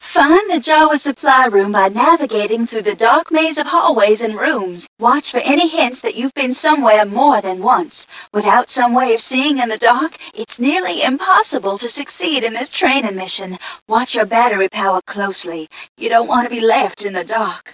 mission_voice_t8ca001.mp3